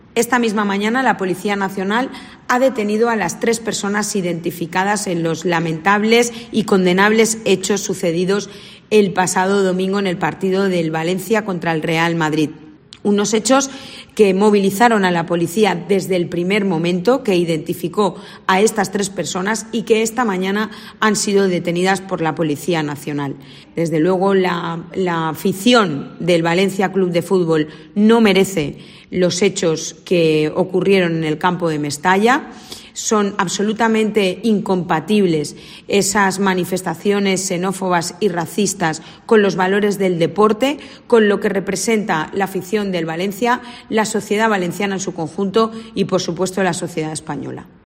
AUDIO. La delegada del Gobierno, Pilar Bernabé, confirma las detenciones